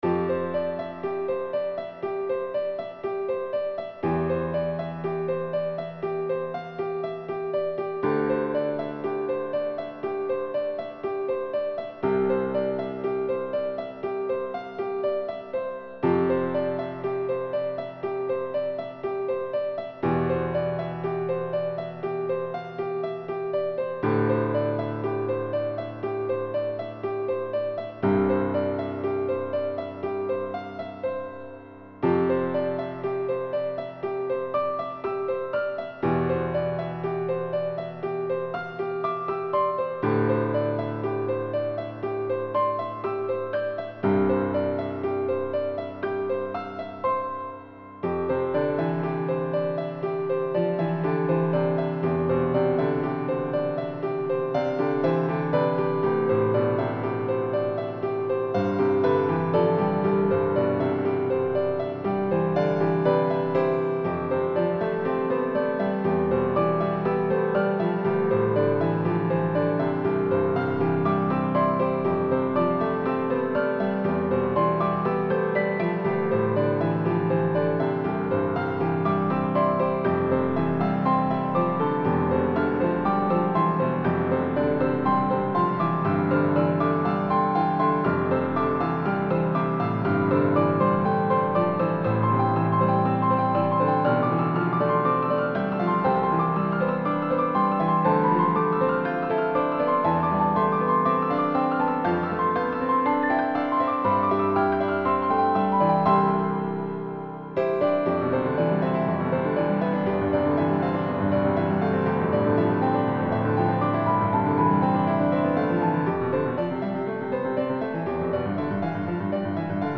雲の間隙から光が差し込んで優しく包み込むような感じ、がずっと頭にあったのですがどうでしょう？
さすがにそれだけだとメロディの薄いこの曲では少し寂しいかな？と、低音を持続させるようにしました。
エンディングに近い場所でのBGMをイメージした単調なフレーズの繰り返しですけど、自分では結構気に入ってたり。